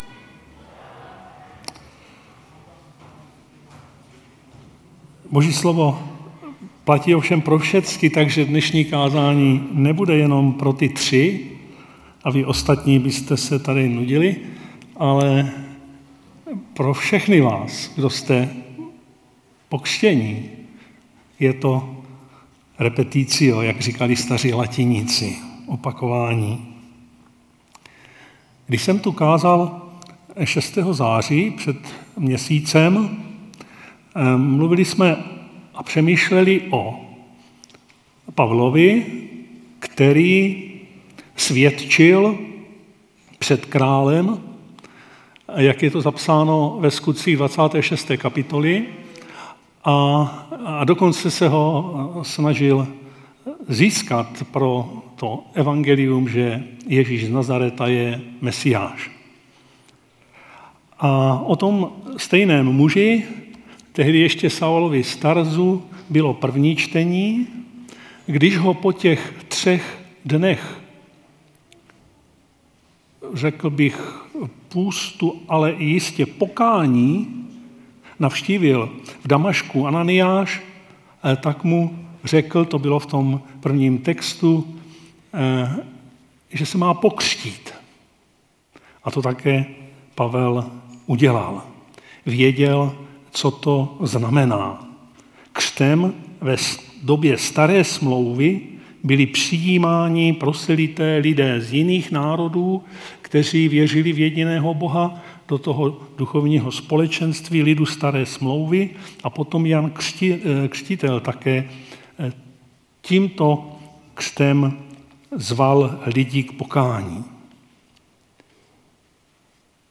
Křestní bohoslužba